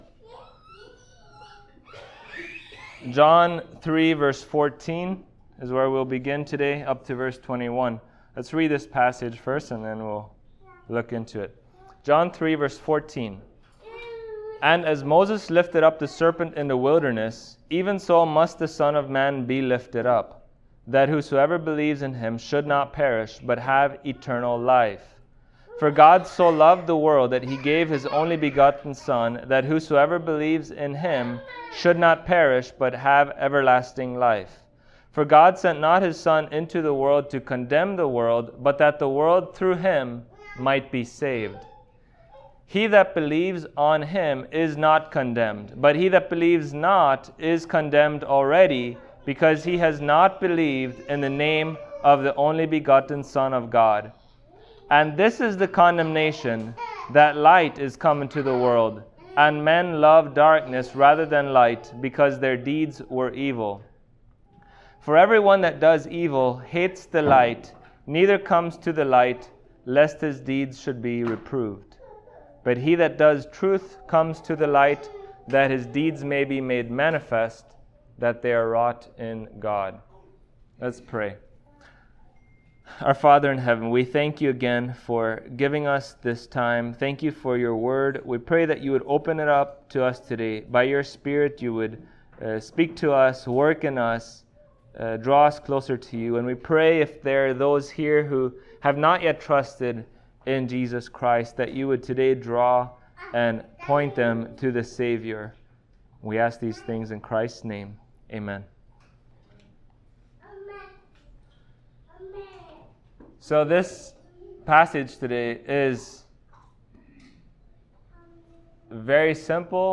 Passage: John 3:14-21 Service Type: Sunday Morning Topics